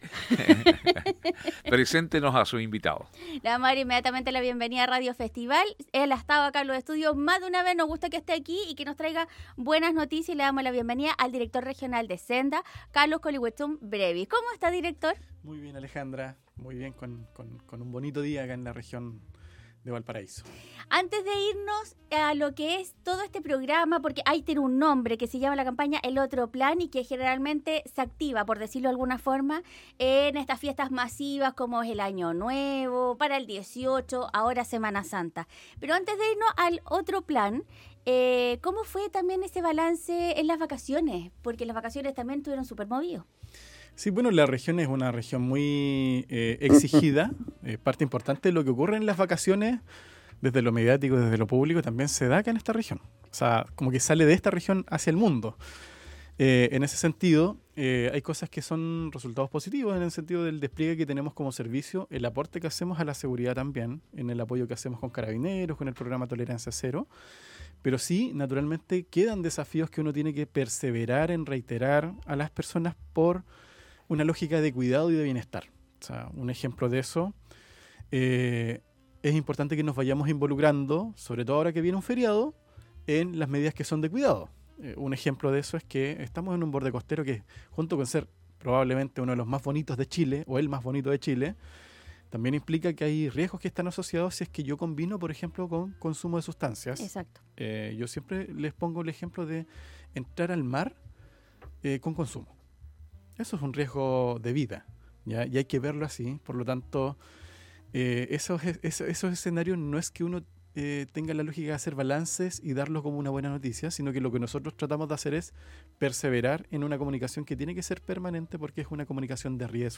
El Director de SENDA Valparaíso, Carlos Colihuechún Brevis conversó con Radio Festival sobre el despliegue que realizan este Fin de semana en la zona con temas preventivos de alcohol y drogas.